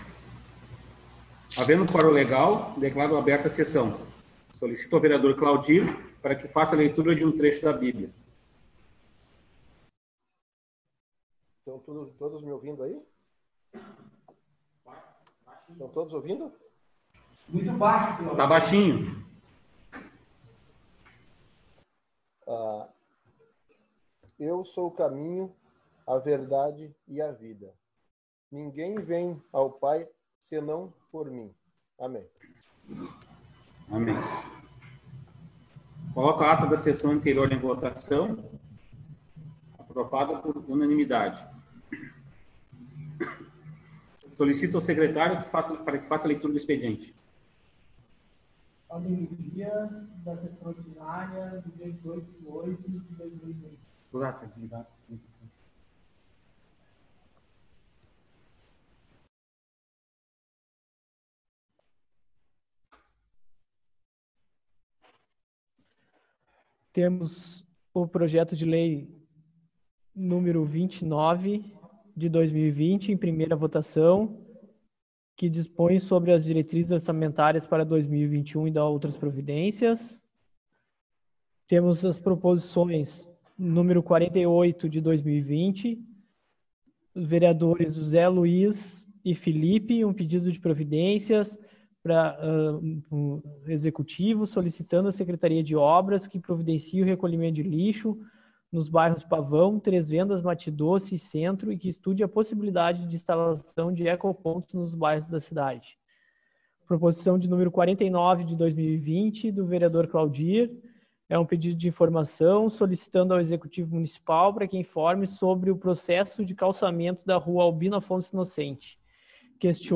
5ª Sessão Online.